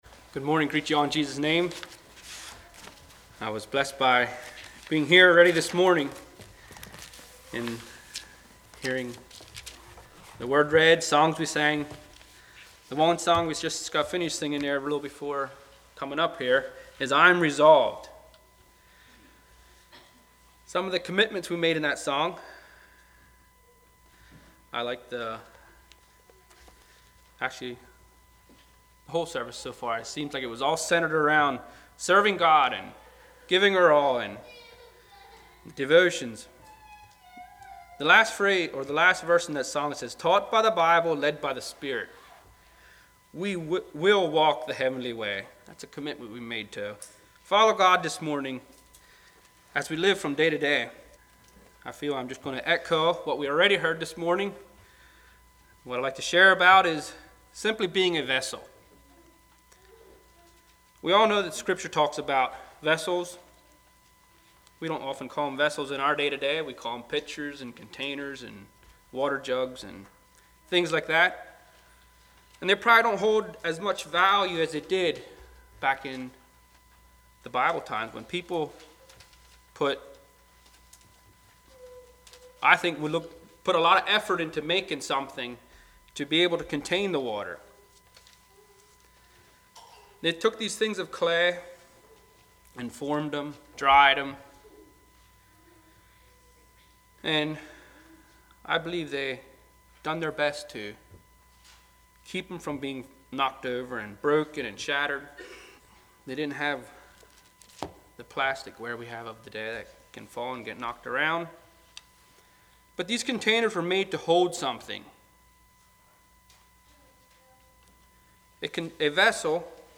2018 Sermon ID